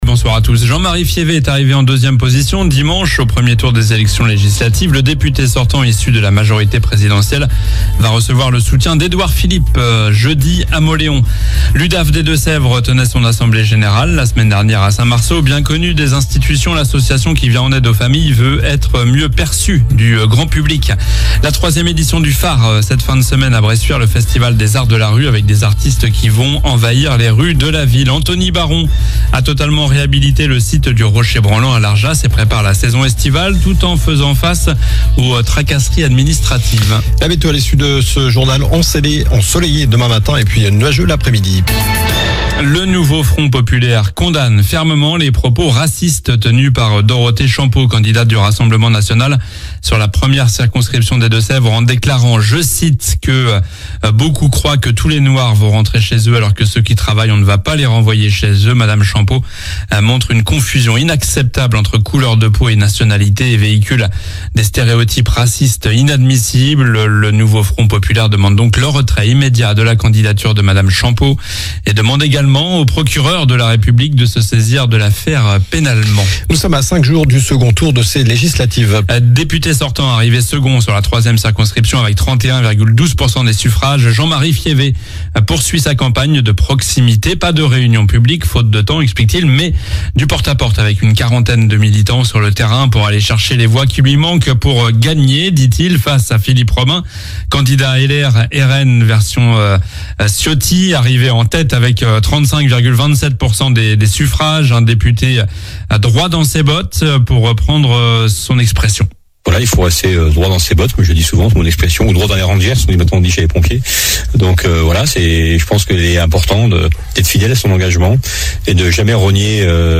Journal du mardi 2 juillet (soir)